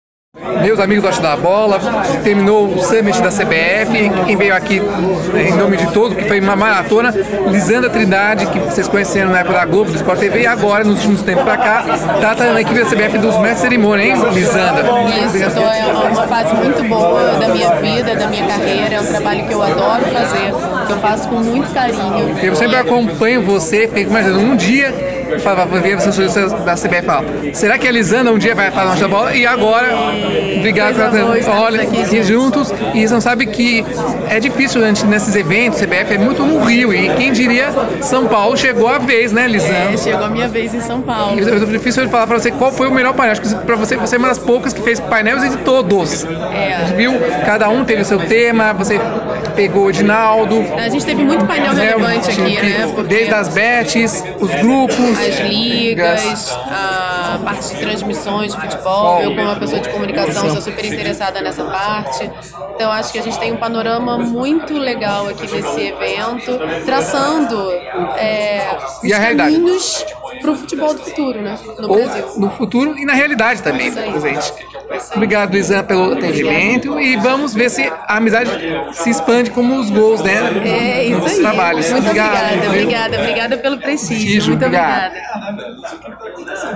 Aqui é a Entrevista